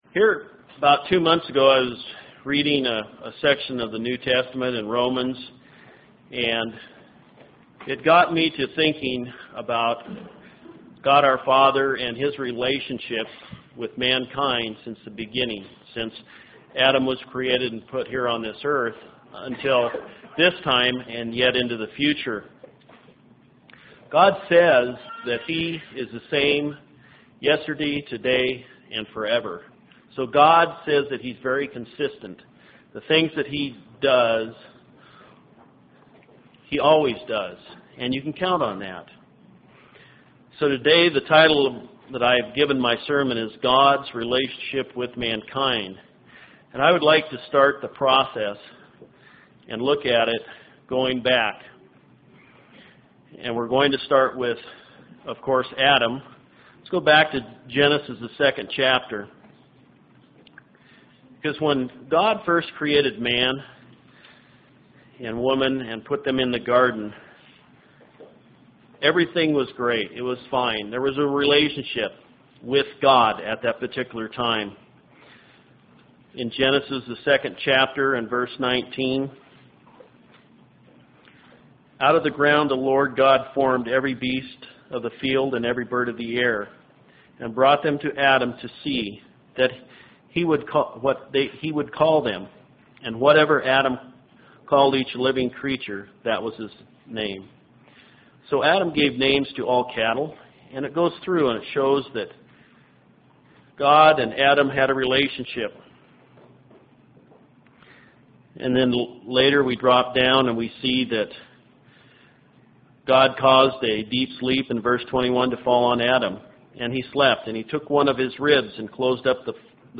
Given in Spokane, WA
UCG Sermon Studying the bible?